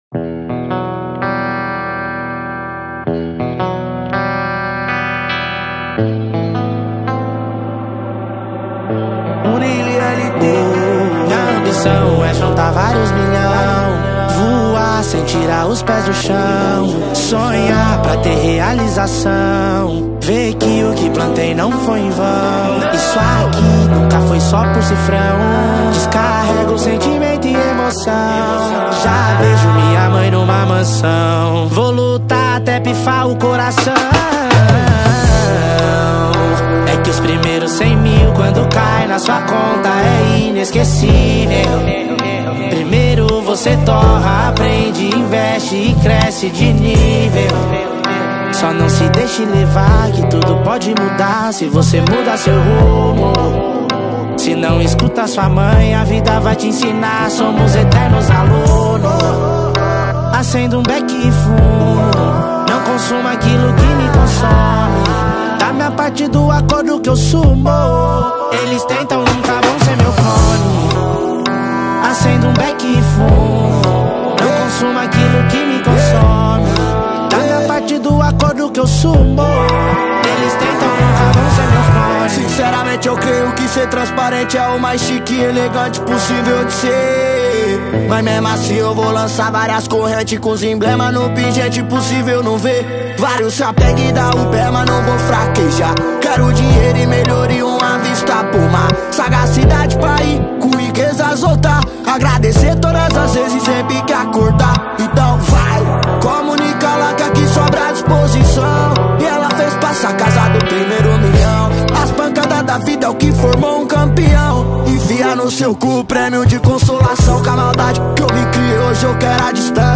2025-02-01 19:08:23 Gênero: Funk Views